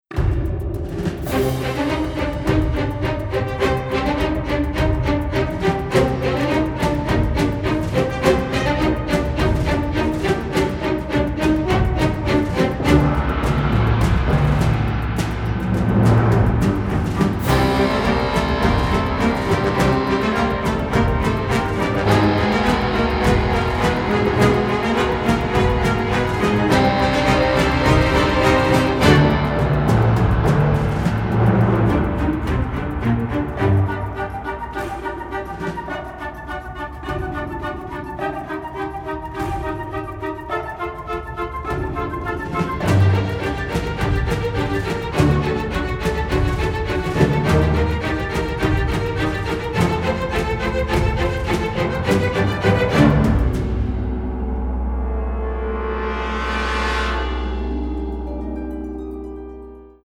The music was recorded in magnificent sound in London